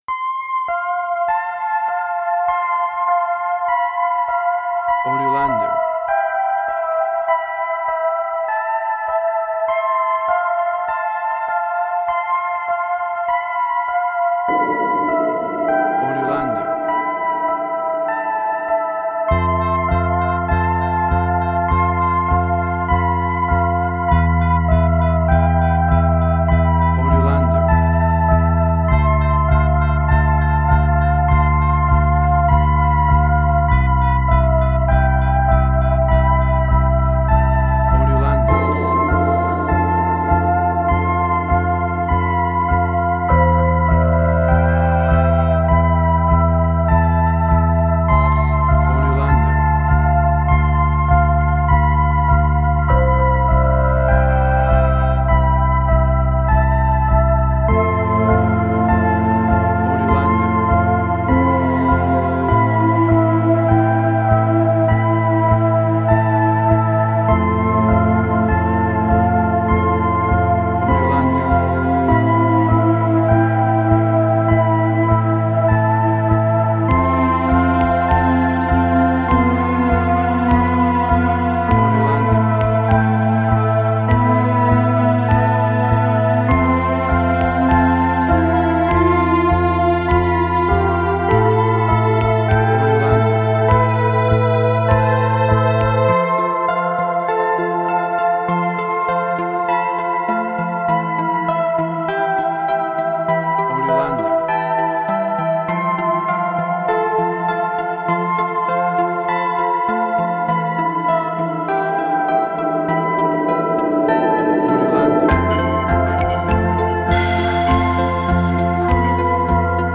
Post-Electronic.
Tempo (BPM): 100